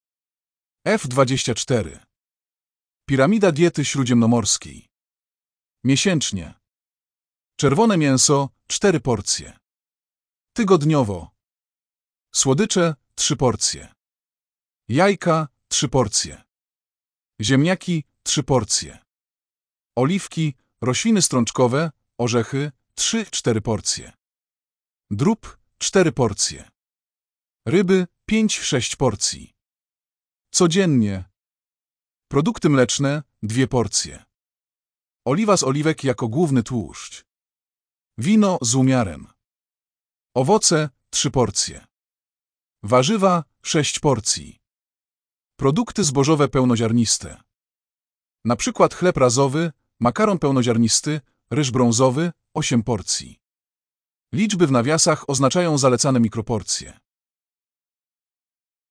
Wycieczka z przewodnikiem audio